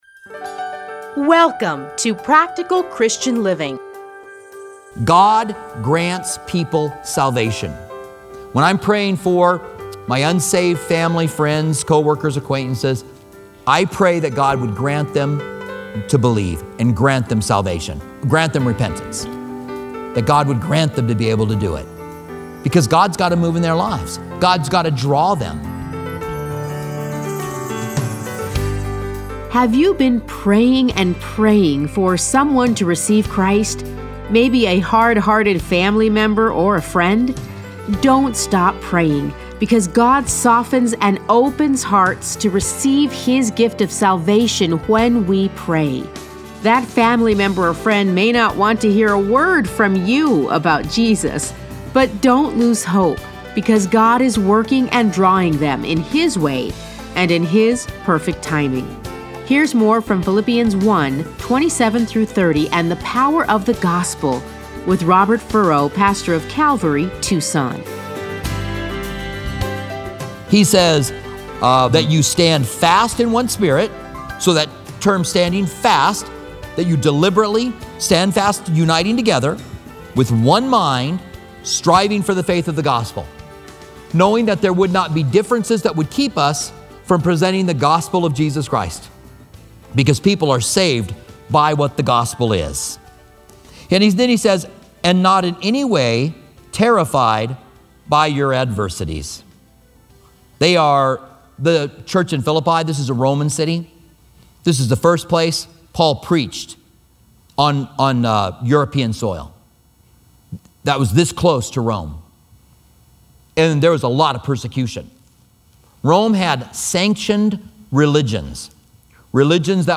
Listen to a teaching from Philippians 1:27-30.